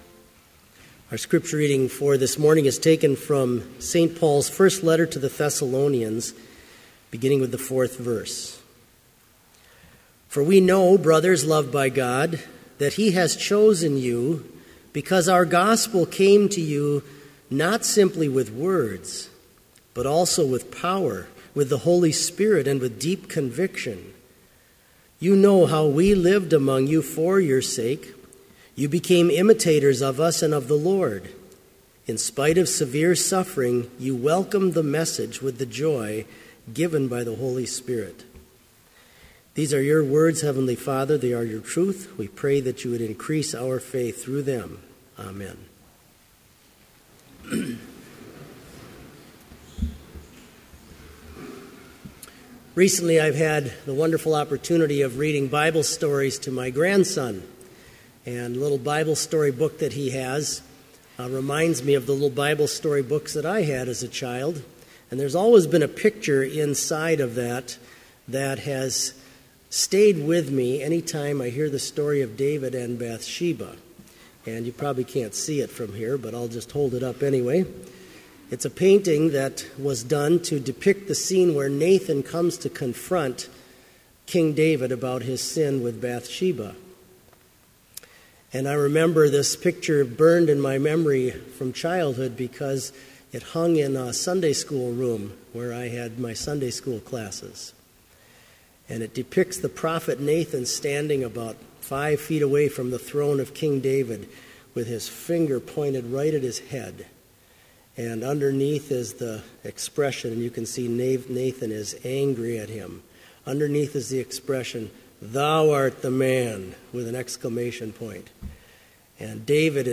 Complete service audio for Chapel - February 2, 2015
Hymn 11, vv. 1, 2 & 5, Come, Holy Ghost in Love